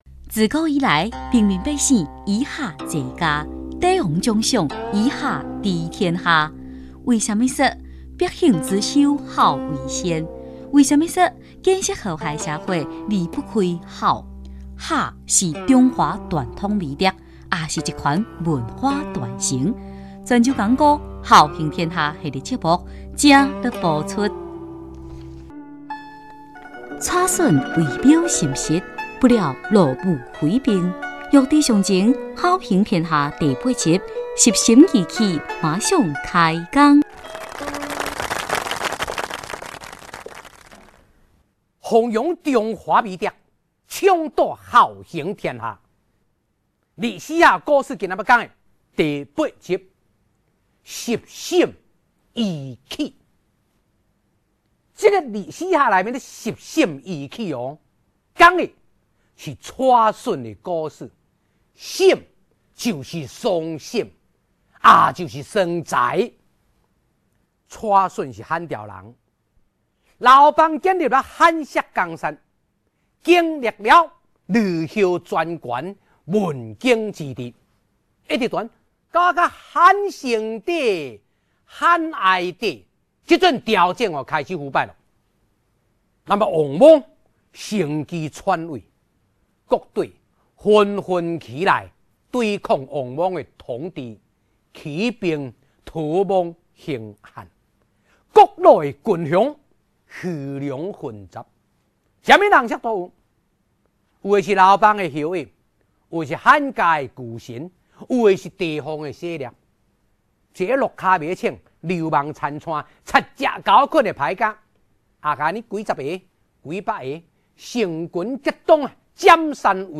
泉州讲古《孝行天下》第八集